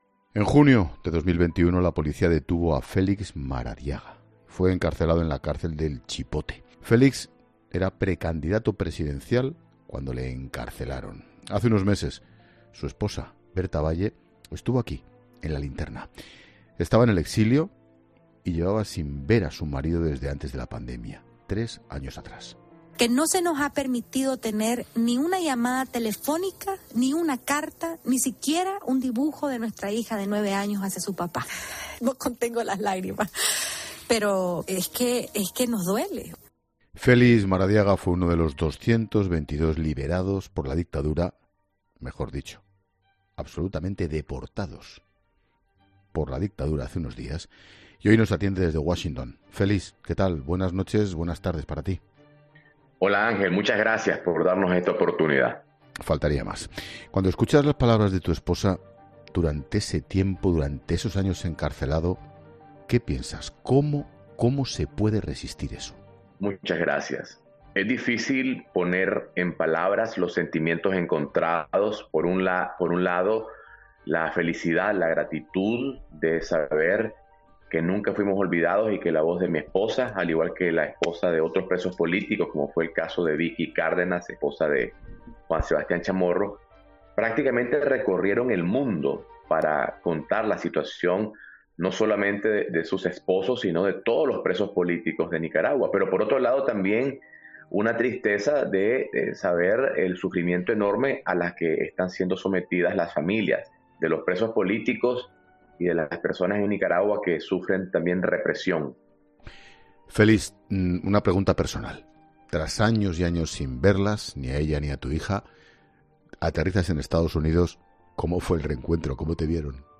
El que fuera candidato presidencial en el país centroamericano relata en La Linterna cómo fue el momento de su traslado desde prisión hasta Washington